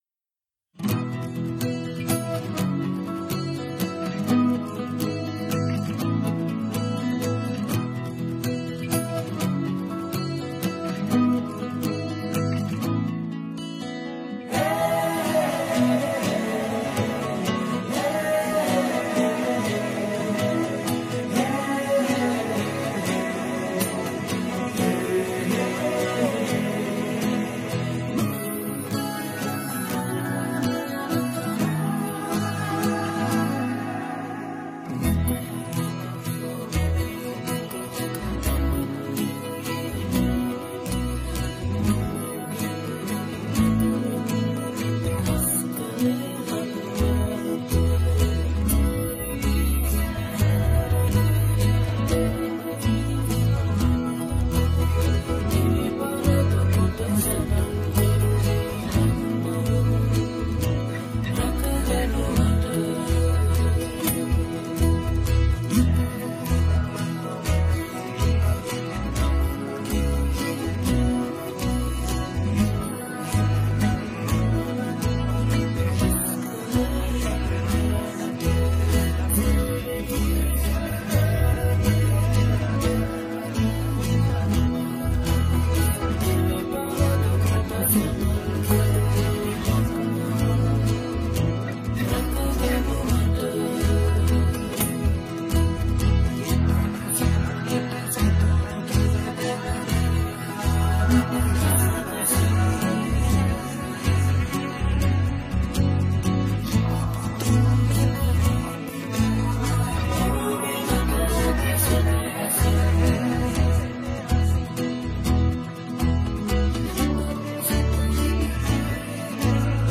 Sing with Us